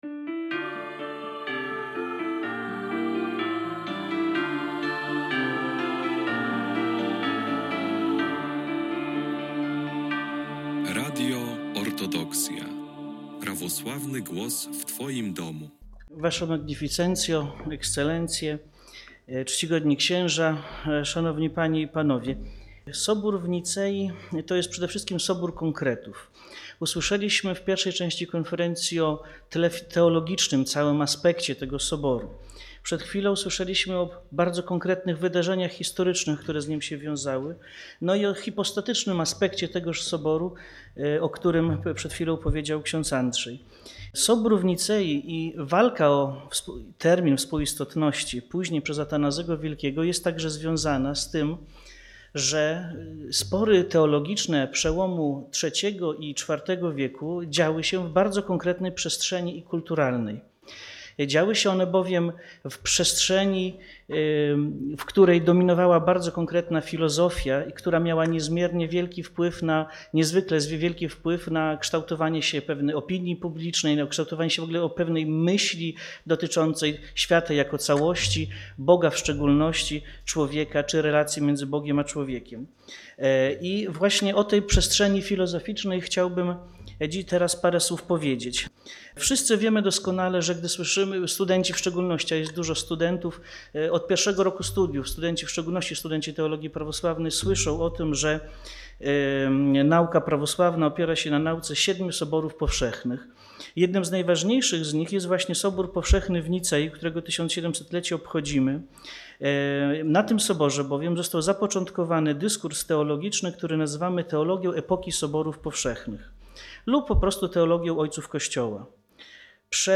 Odbyła się 28 października 2025 w budynku ChAT.